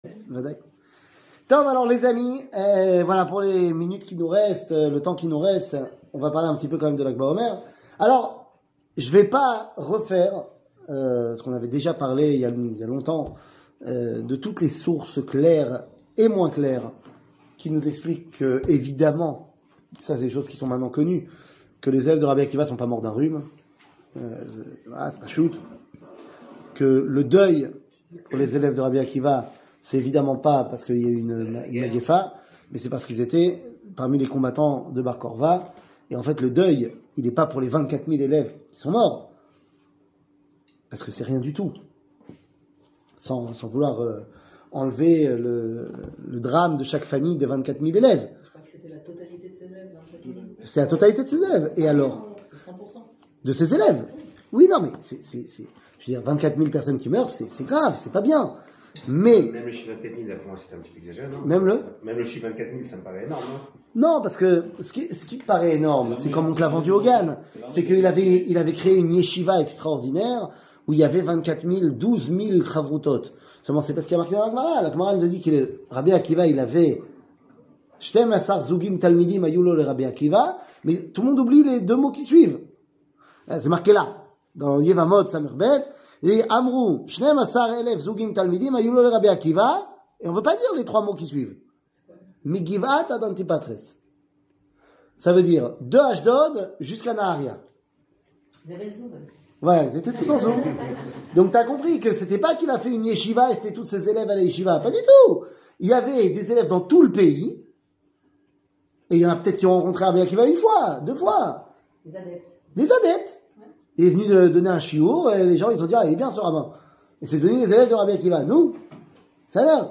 Lag Baomer, Entre Rabbi Meir et Rabbi Chimon 00:24:11 Lag Baomer, Entre Rabbi Meir et Rabbi Chimon שיעור מ 16 מאי 2022 24MIN הורדה בקובץ אודיו MP3 (22.14 Mo) הורדה בקובץ וידאו MP4 (57.35 Mo) TAGS : שיעורים קצרים